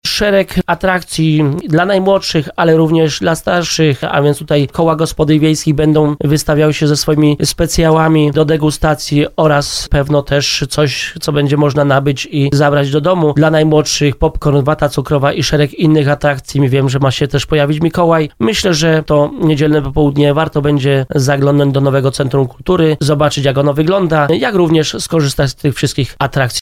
Jak mówi wójt Sławomir Paterek, przygotowano wiele atrakcji dla każdego.